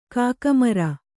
♪ kāka mara